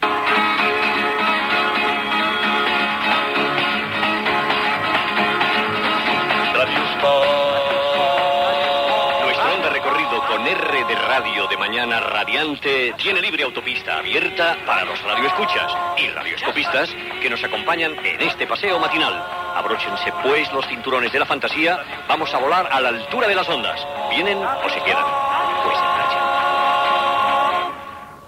Promoció del programa
Fragment extret del programa "La radio con botas" de Radio 5, emès l'any 1991